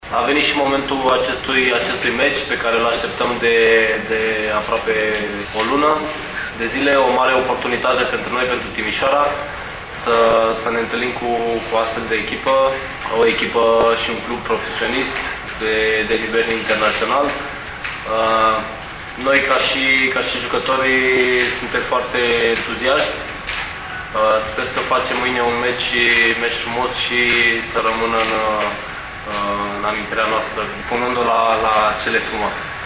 Mai jos puteți asculta declarațiile celor doi căpitani de echipă